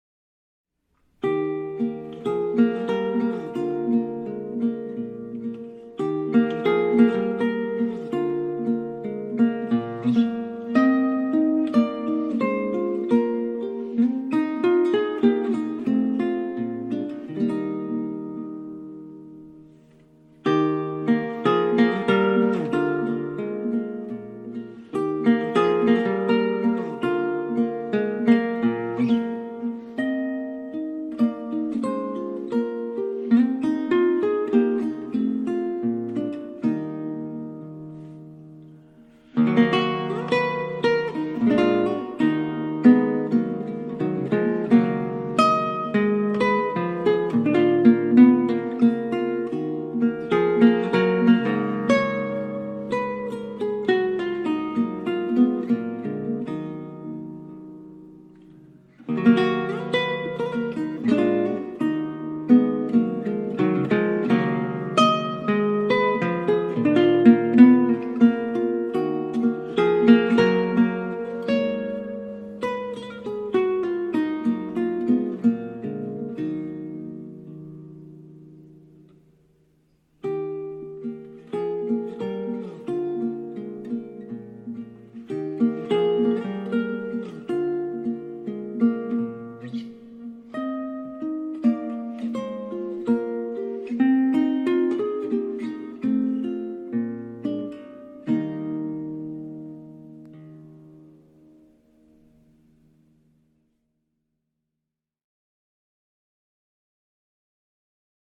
LágrimaOn-Original-19th-Century-Guitar.mp3